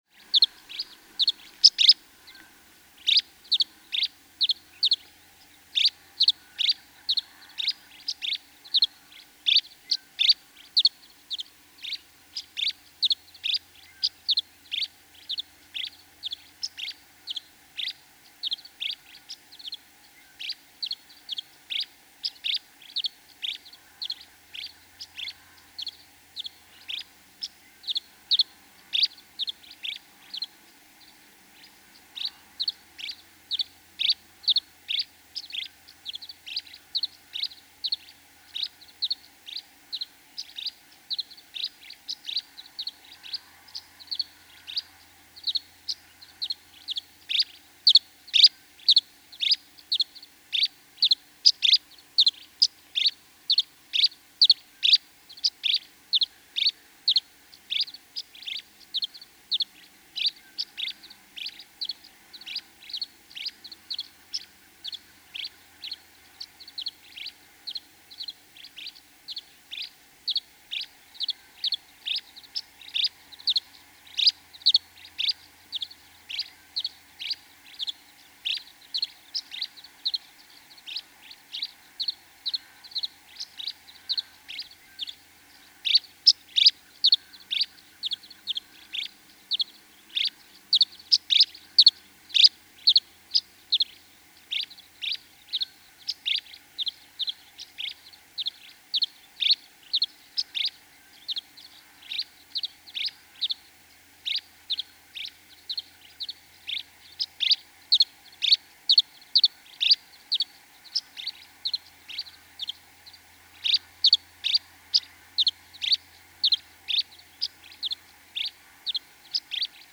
Tree swallow
Subchapter: Songs and calls in flight
About an hour before sunrise, the male sings high overhead in an elliptical flight over his chosen nest site, and I do my best to track him with the parabolic microphone.
William L. Finley National Wildlife Refuge, Corvallis, Oregon.
542_Tree_Swallow.mp3